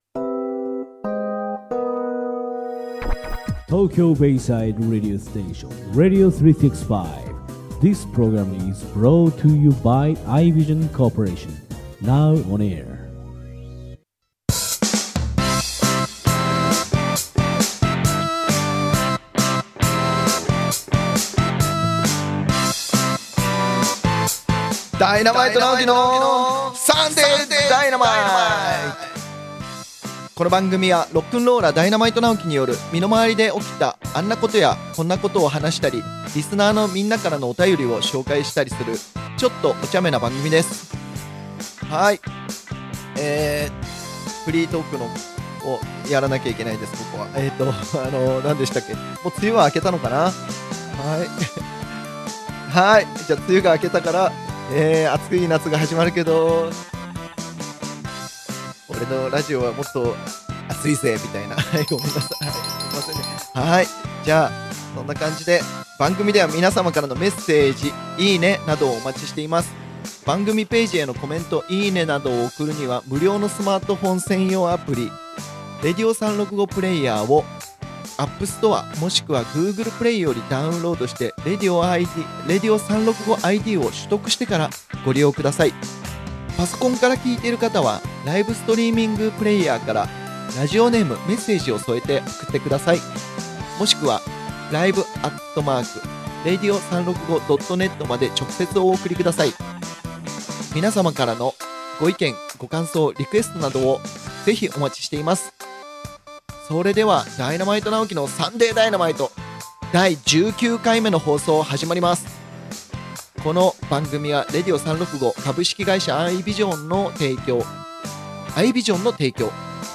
【この音源は生放送のアーカイブ音源となります。】